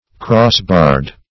Search Result for " crossbarred" : The Collaborative International Dictionary of English v.0.48: Crossbarred \Cross"barred`\ (-b[aum]rd`), a. 1.
crossbarred.mp3